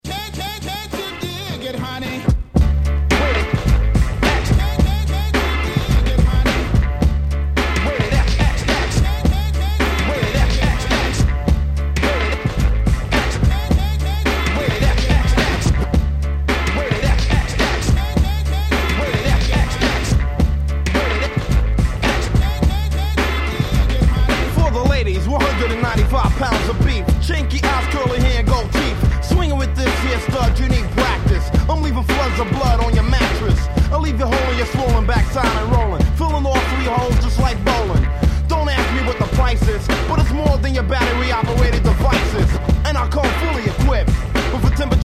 90' Super Hip Hop Classic !!